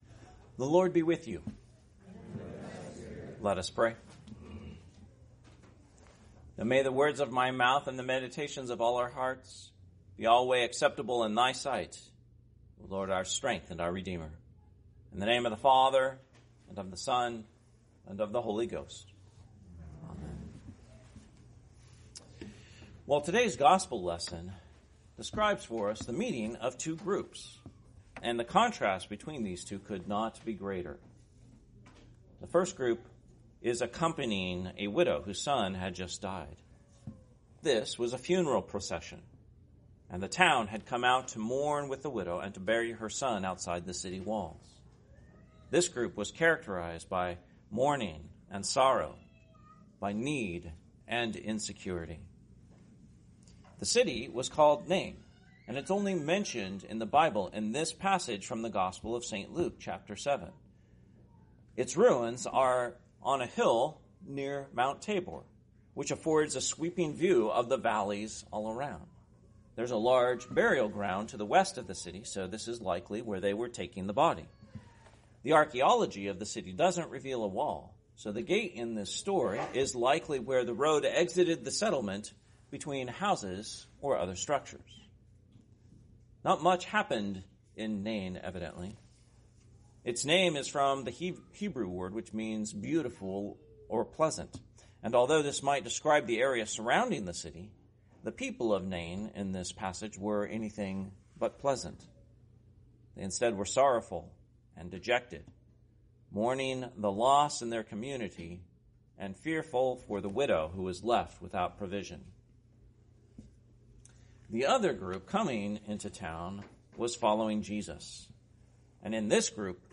Sunday Morning Worship, 16th Sunday after Trinity, Oct. 5, 2025
In today’s homily, we look at the miracle of Christ’s raising the widow of Nain’s son from the dead. The narrative paints a picture of two groups meeting at the city gates.